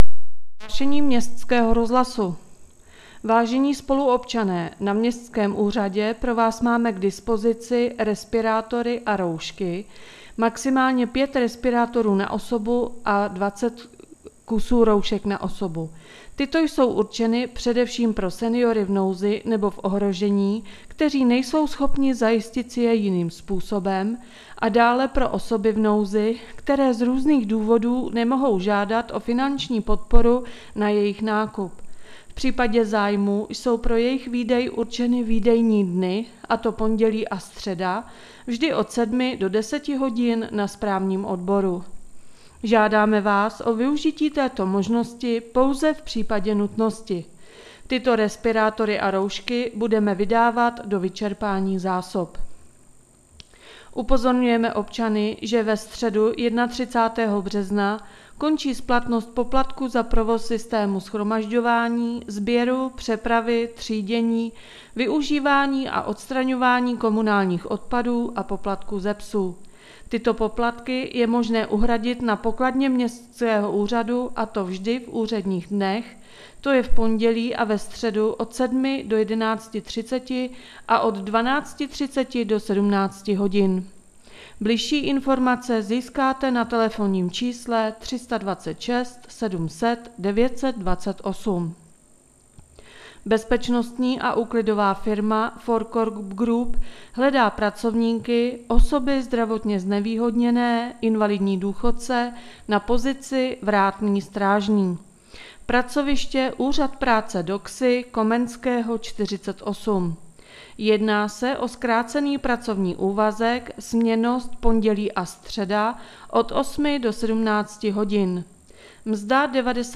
Hlášeníé městského rozhlasu 22.3.2021